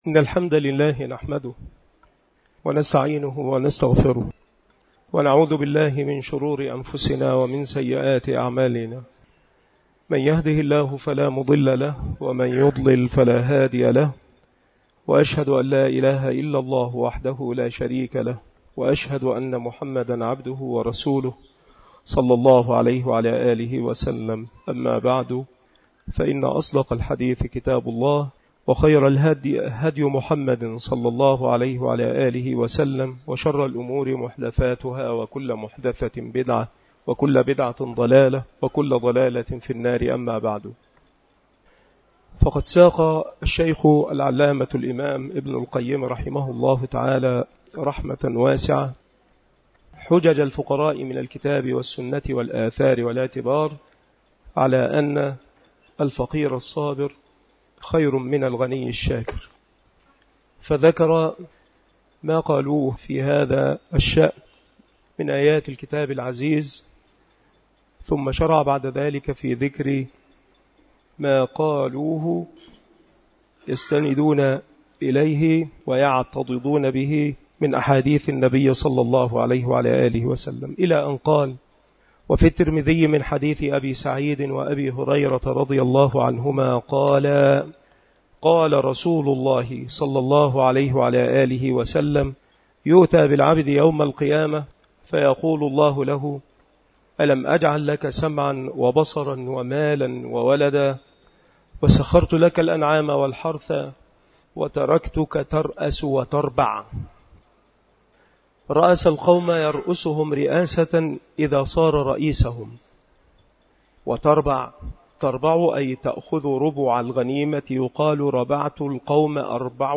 مكان إلقاء هذه المحاضرة بمسجد أولاد غانم بمدينة منوف - محافظة المنوفية - مصر